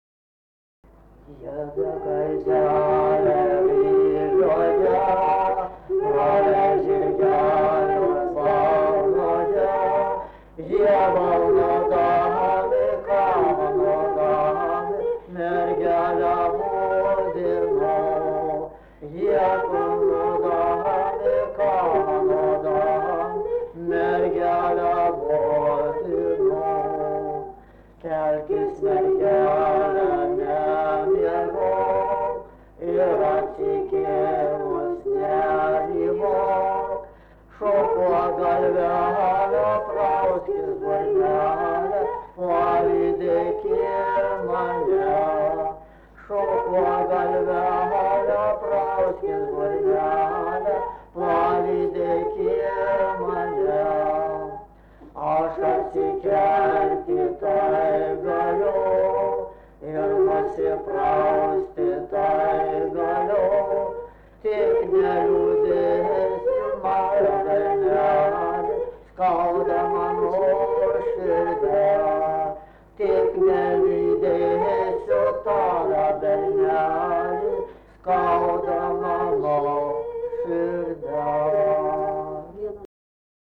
Subject daina
Erdvinė aprėptis Menčikiai
Atlikimo pubūdis vokalinis